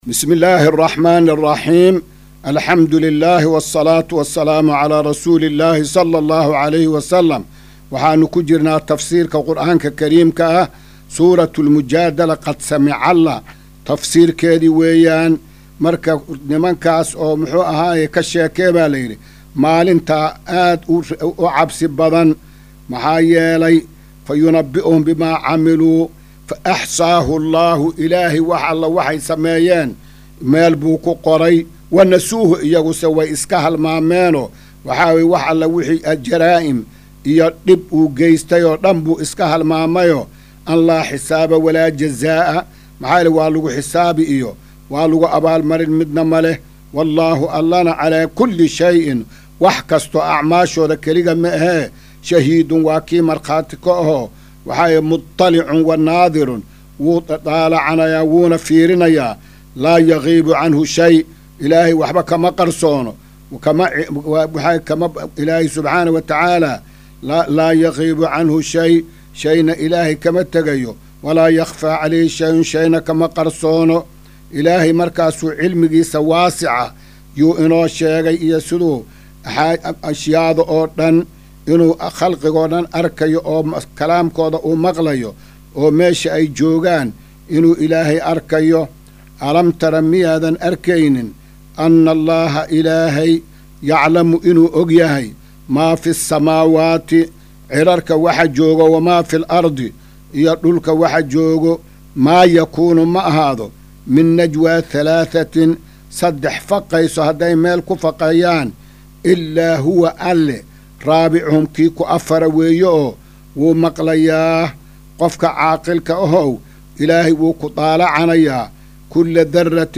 Maqal:- Casharka Tafsiirka Qur’aanka Idaacadda Himilo “Darsiga 259aad”